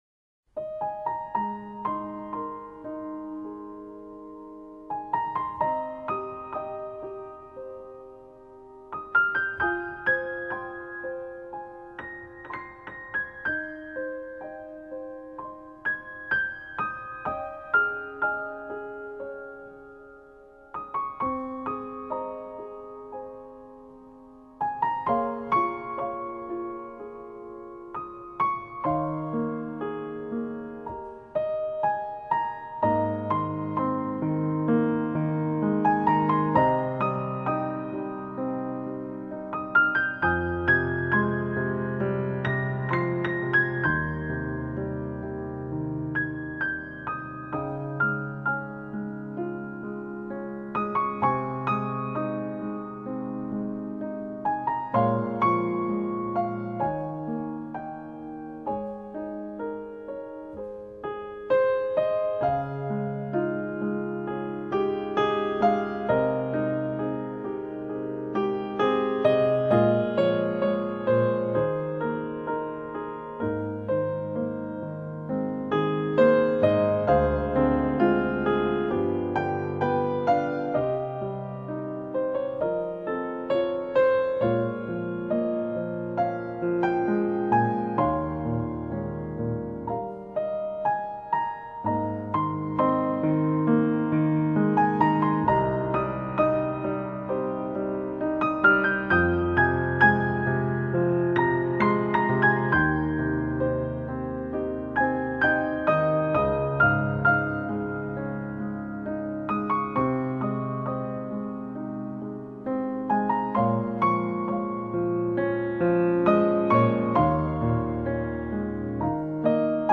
Genre: New Age, Neo-Classical, Piano